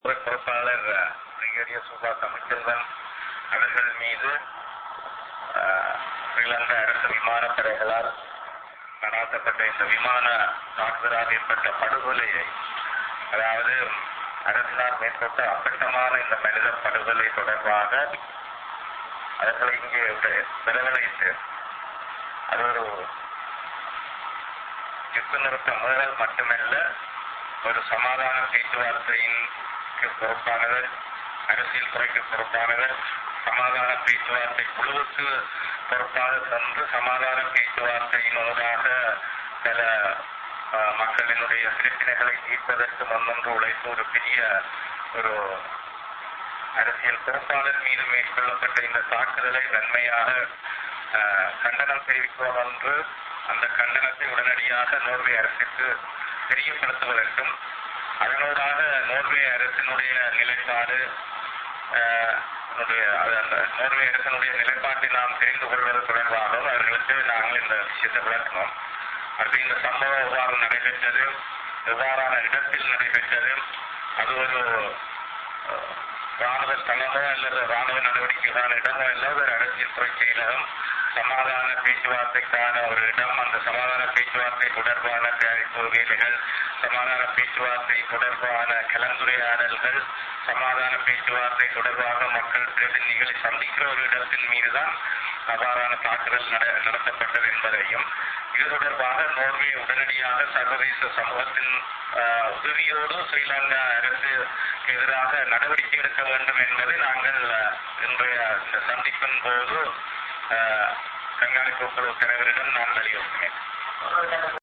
Voice: Press briefing by P. Nadesan